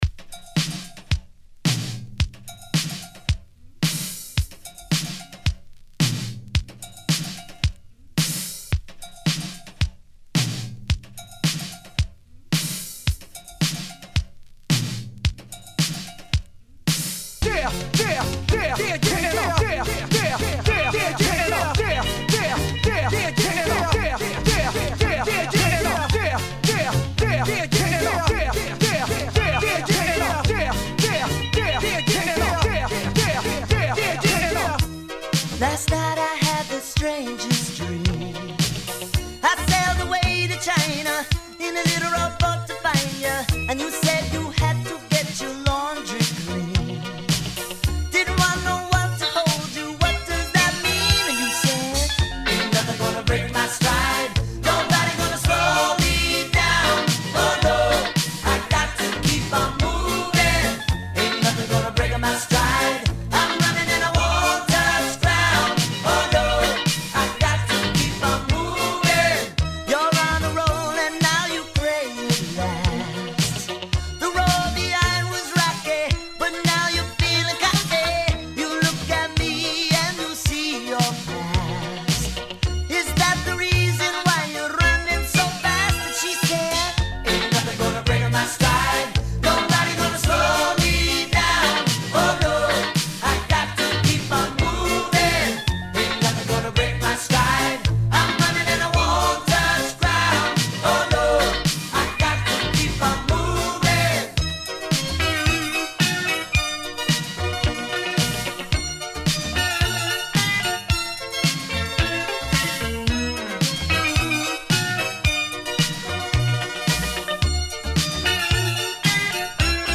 105 Bpm Genre: 70's Version: Clean BPM: 105 Time